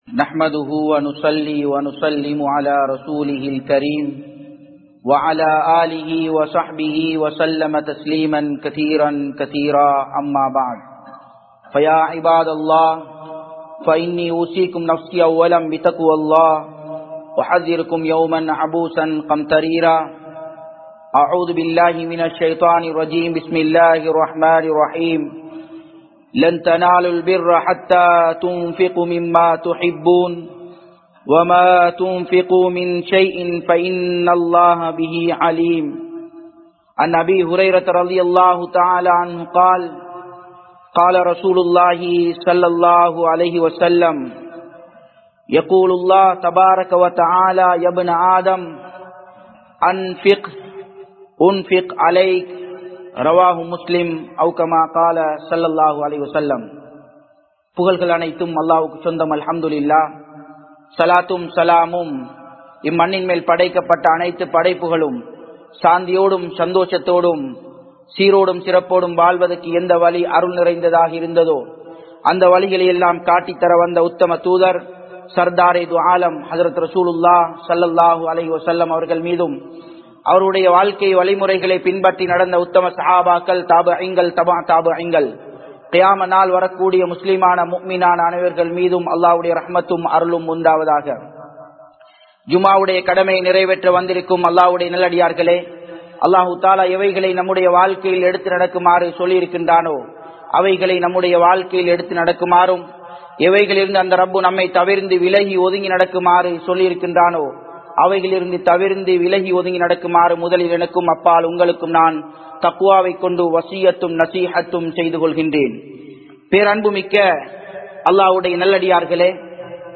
Marumaikkaaha Selvangalai Selvaliungal (மறுமைக்காக செல்வங்களை செலவழியுங்கள்) | Audio Bayans | All Ceylon Muslim Youth Community | Addalaichenai